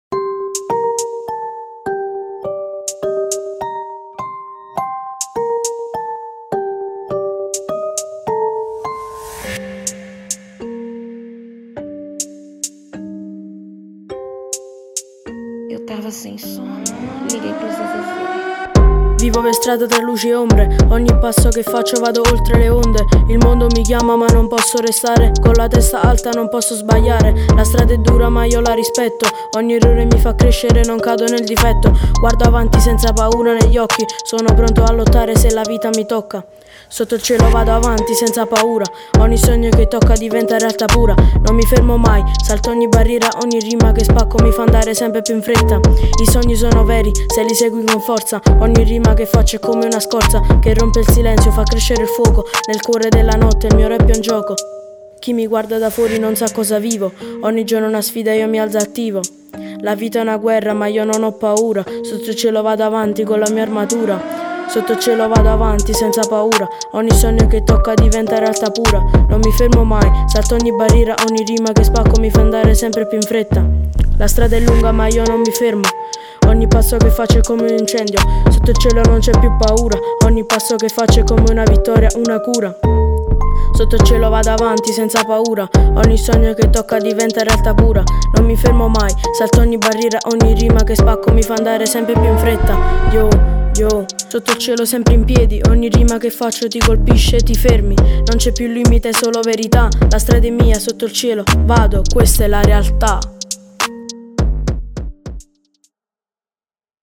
musica rap